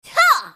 slayer_f_voc_attack02_d.mp3